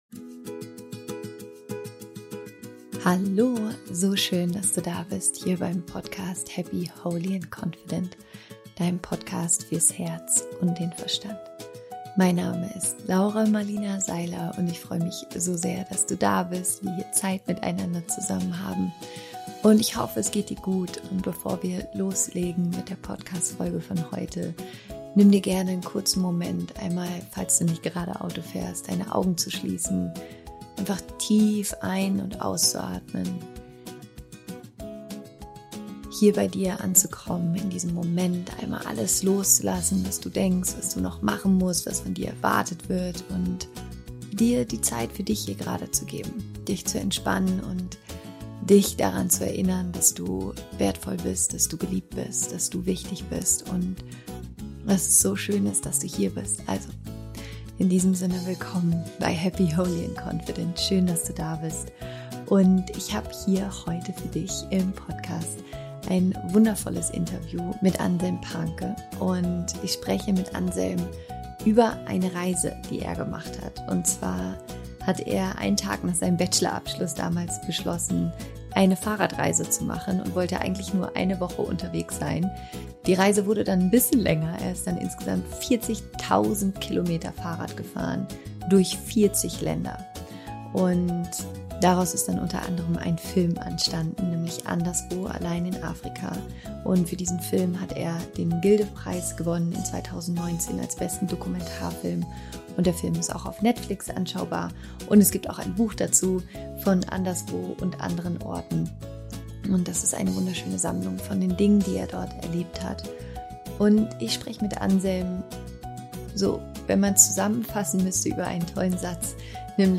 Im Interview erzählt er uns, wie seine Suche nach Antworten und sein Wunsch, sich selbst zu finden, ihn mit seinen größten Ängsten konfrontierte.